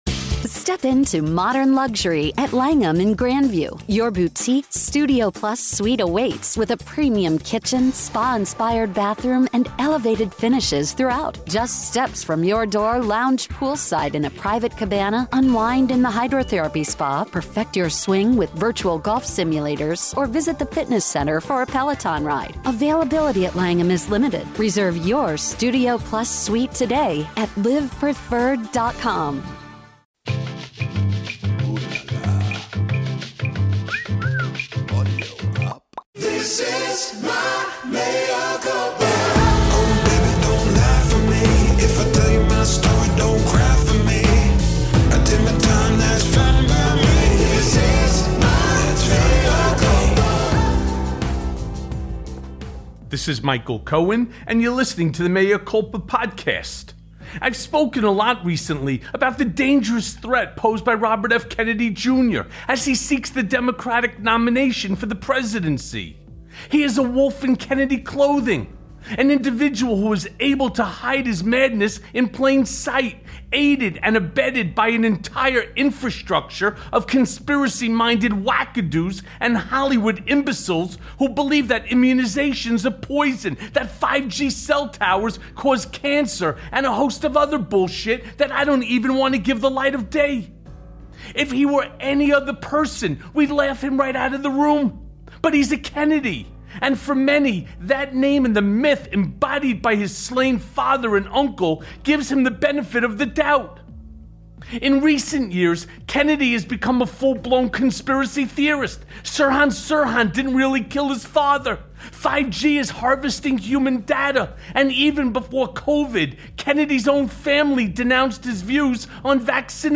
EXCLUSIVE!!! Robert Kennedy Jr. Vs. Michael Cohen…If You Thought He Was Crazy Just Wait Until Your Hear This Interview